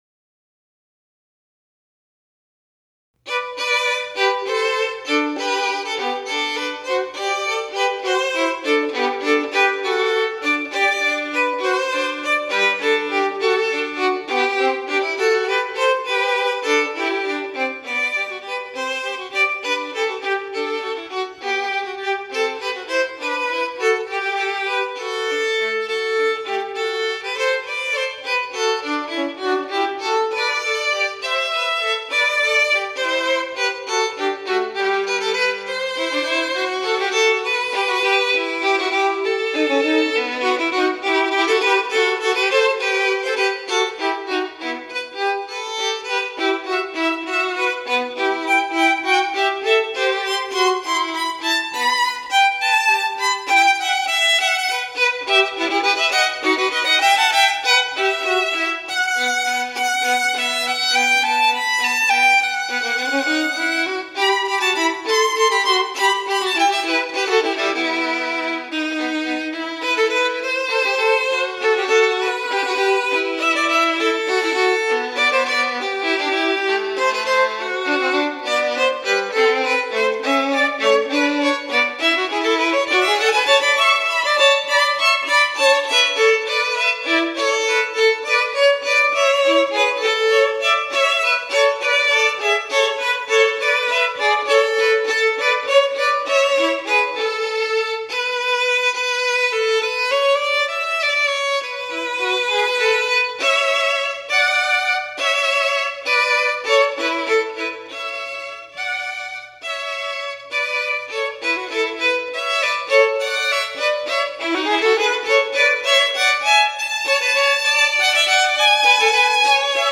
2 violins no piano accompaniment